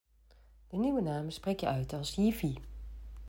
'yivvy' and hinting at the Dutch for 'your digital life', the new name comes with a new design, reflecting the identity wallet's progression to the next level of maturity.
Uitspraak_Yivi.m4a